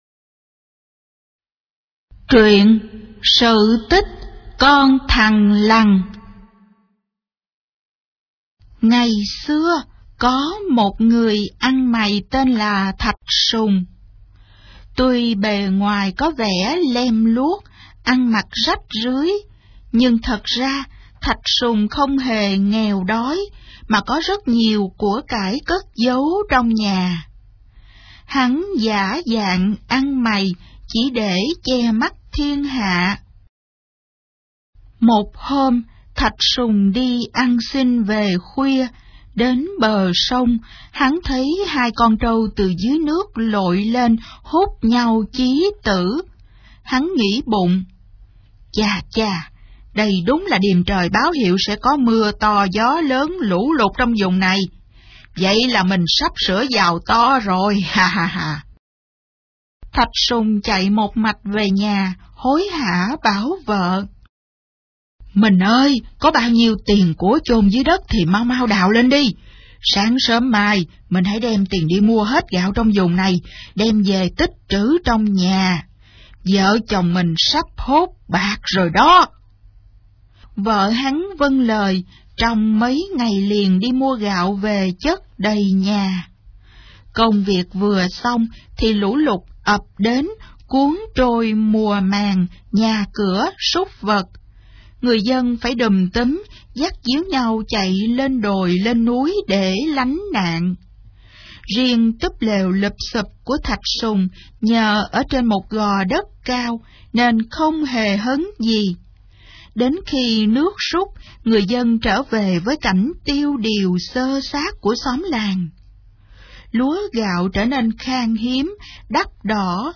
Sách nói | sự tích con thằn lằn